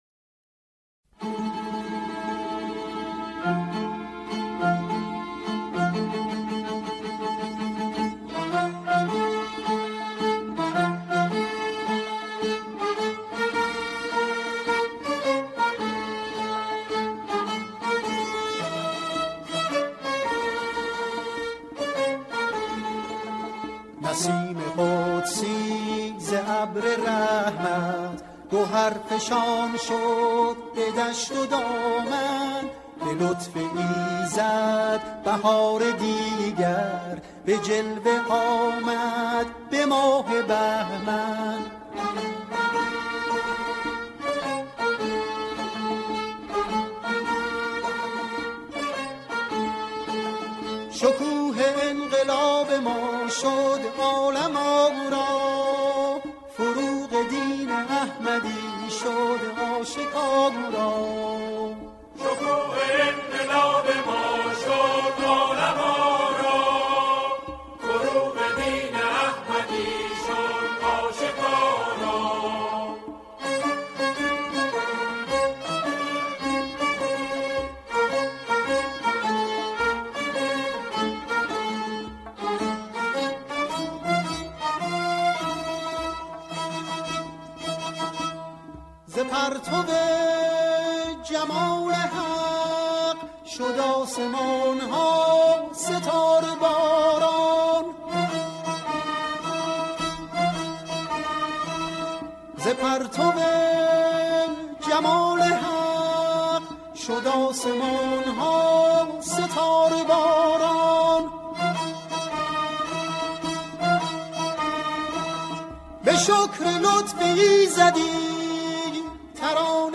همخوانی شعری درباره “دهه فجر”